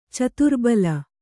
♪ caturbala